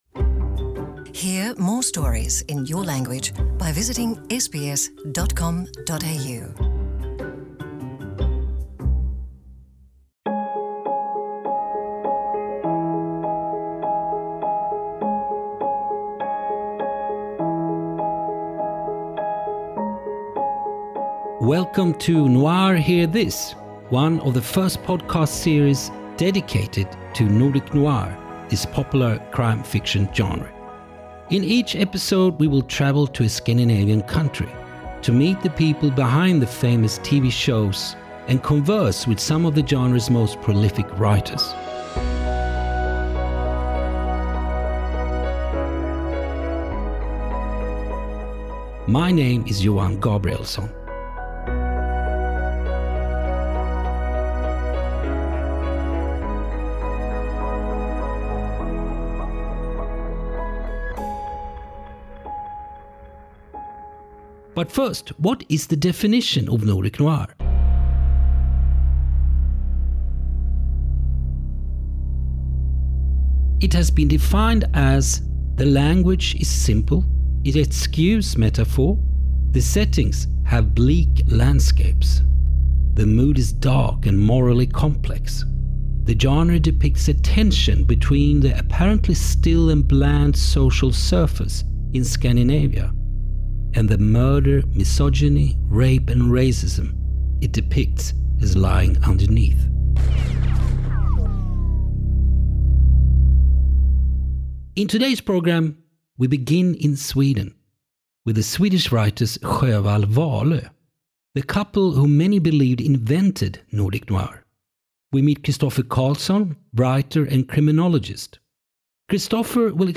This episode's featured book reading is of The Abdominal Man by Maj Sjöwall and Per Wahlöö (Norstedts förlag, 1971).